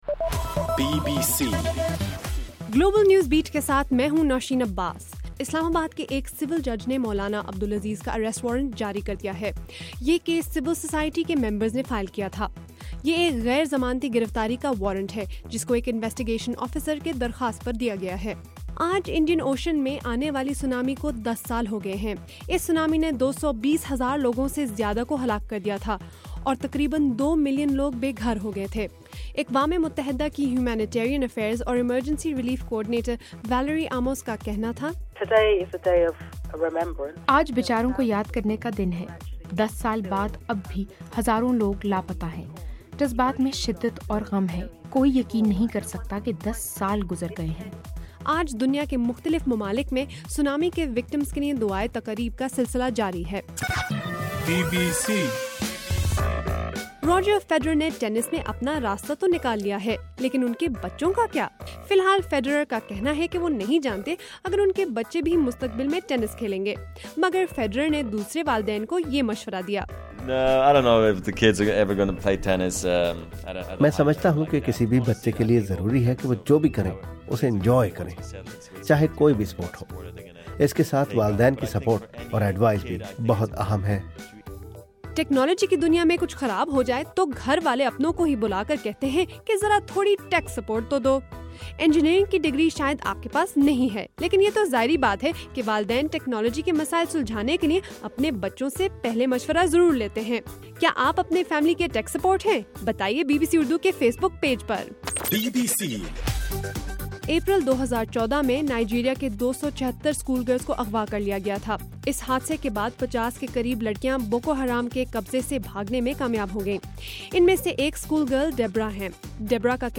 دسمبر 26: رات 8 بجے کا گلوبل نیوز بیٹ بُلیٹن